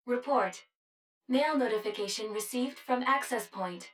153_Mail_Notification.wav